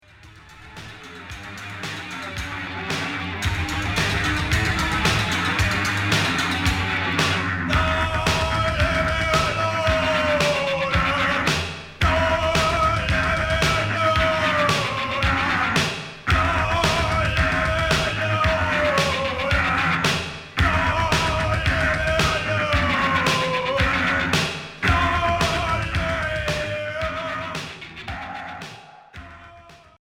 Cold wave indus